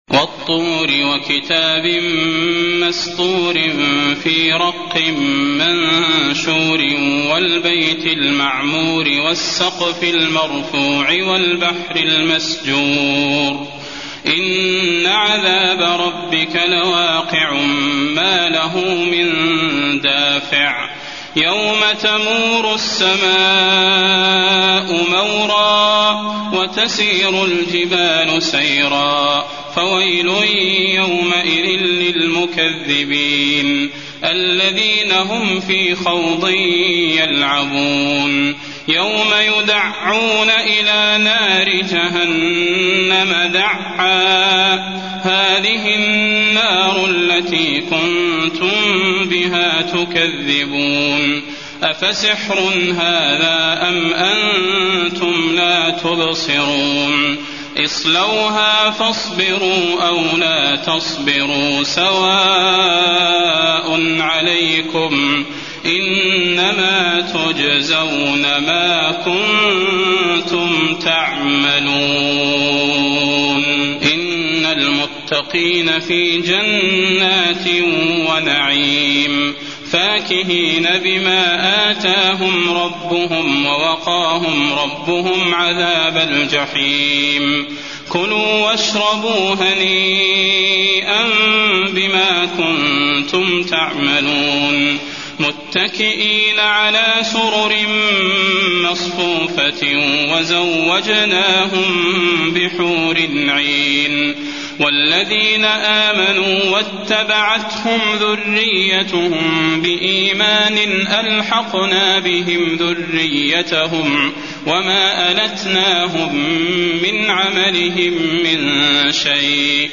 المكان: المسجد النبوي الطور